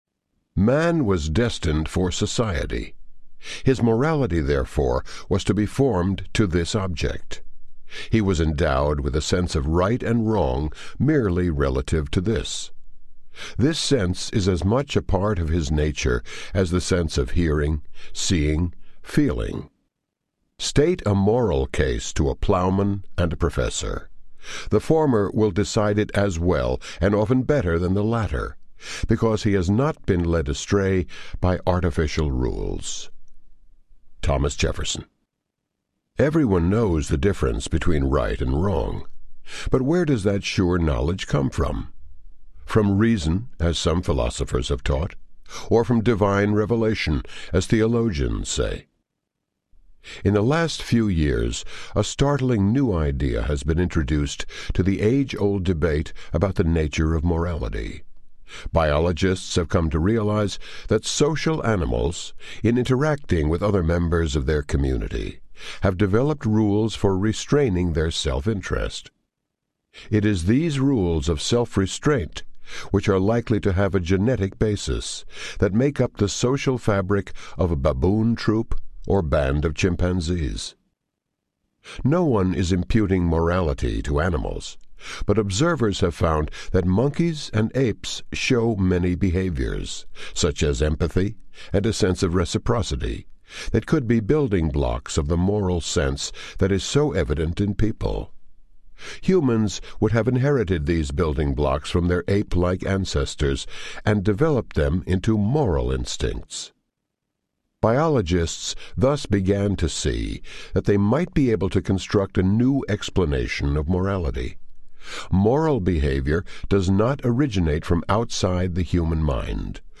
Faith Instinct Audiobook
Narrator